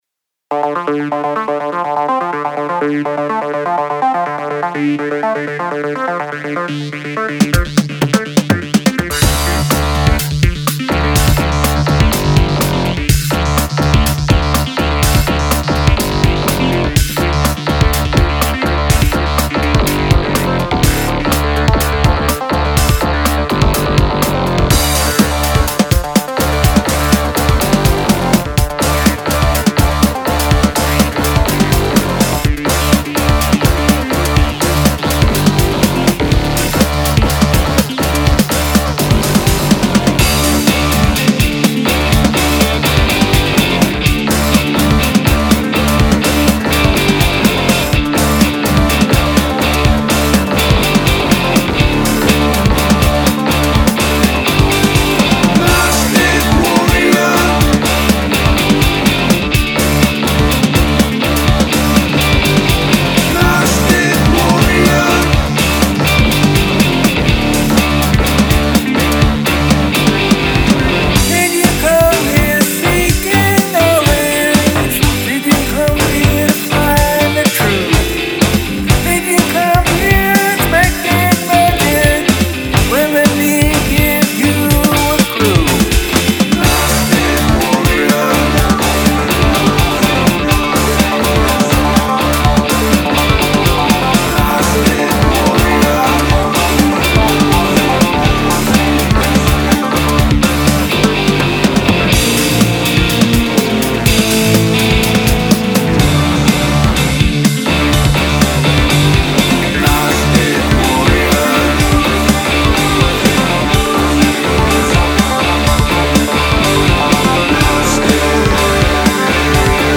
Industrial Rock Tune